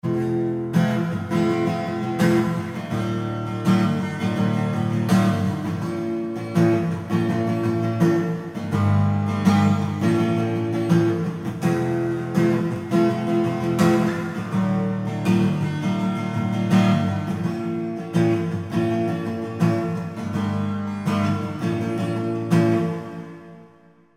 guitar hall reverb deep
guitar-hall.mp3